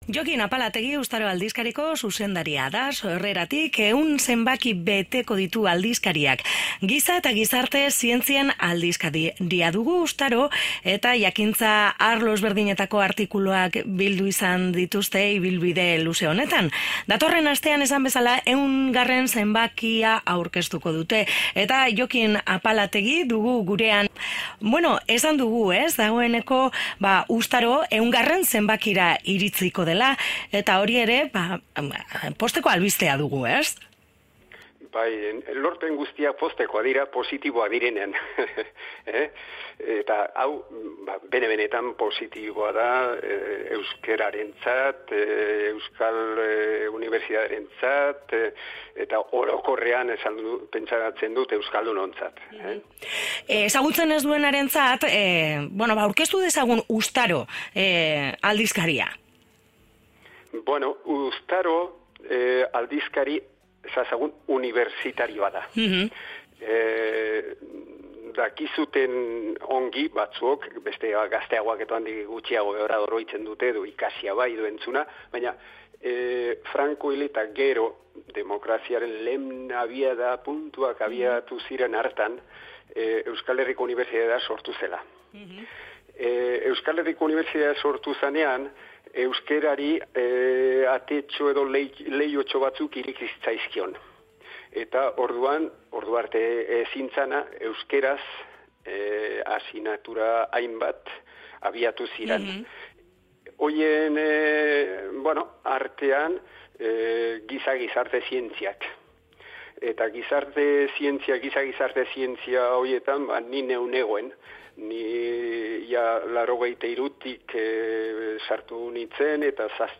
IRRATIEN TARTEA | “Giz’arte” ikuskizunaz eginiko erreportaia ekarri digute Antxeta irratiko lagunek